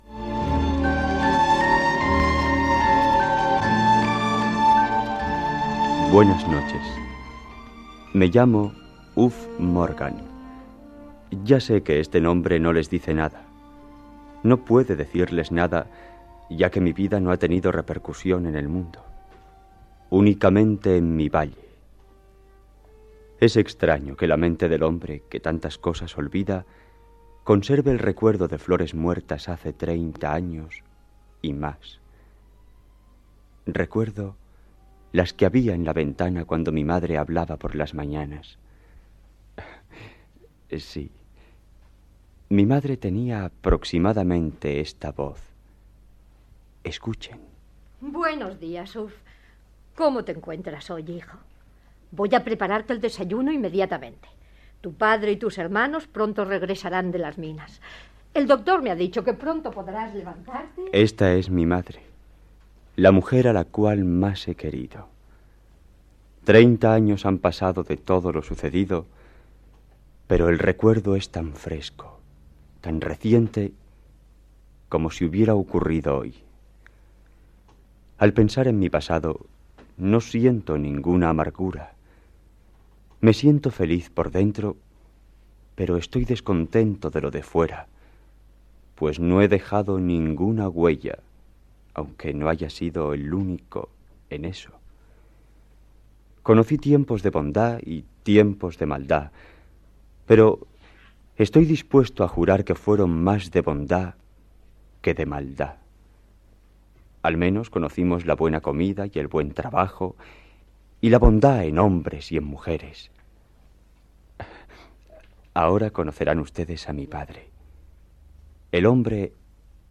Adaptació radiofònica de l'obra "Qué verde era mi valle" de Richard Llewellyn.
Ficció